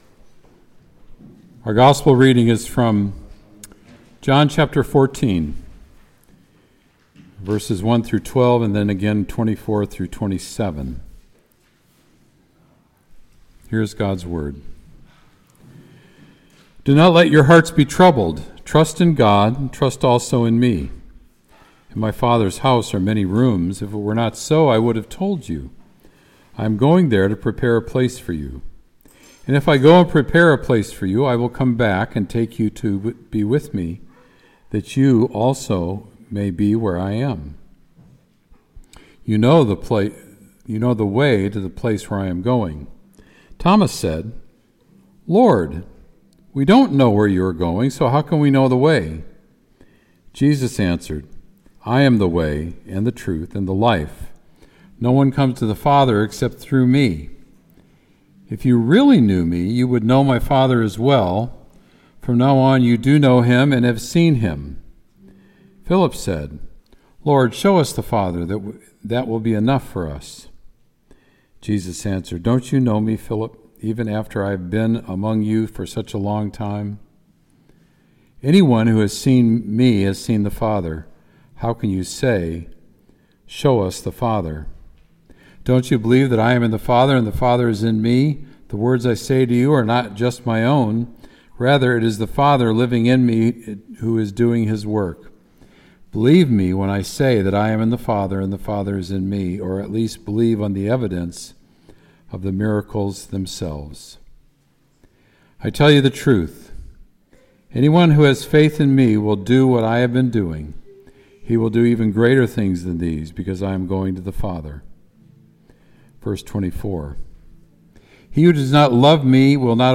Sermon “Heart Trouble”